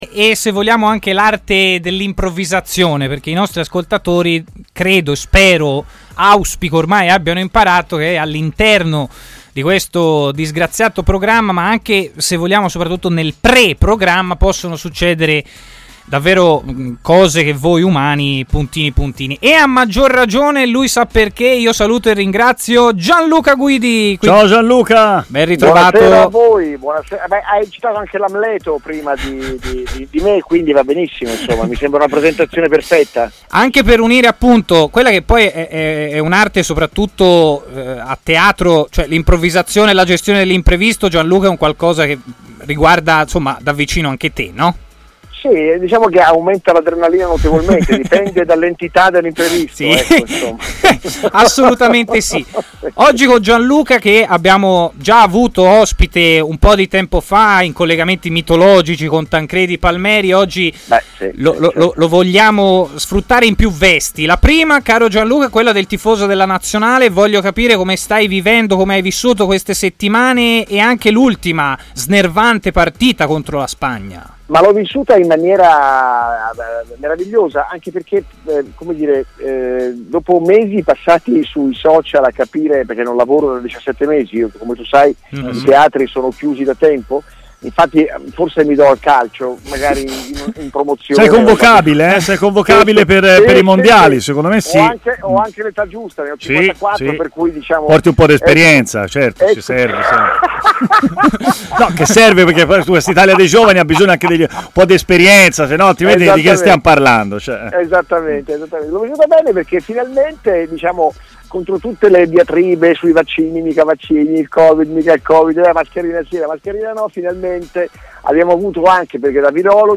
ha parlato in diretta a TMW Radio